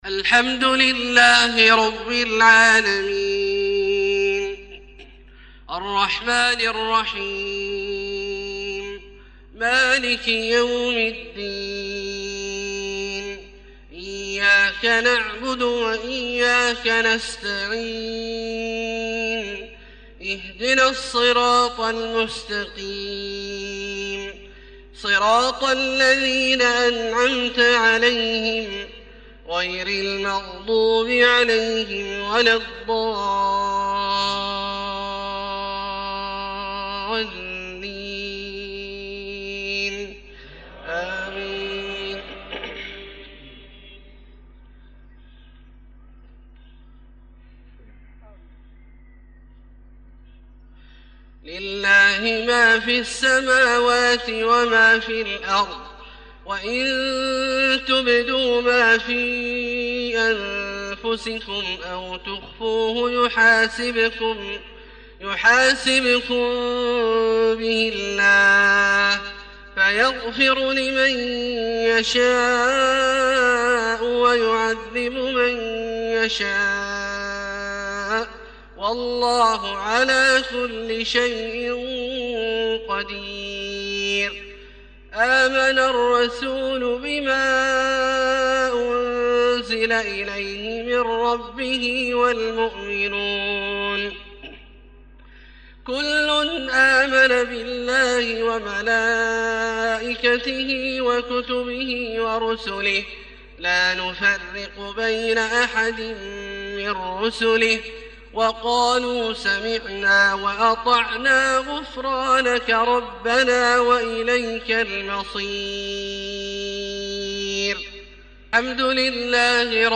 صلاة المغرب 6-5-1432 خواتيم سورة البقرة{284-286} > ١٤٣٢ هـ > الفروض - تلاوات عبدالله الجهني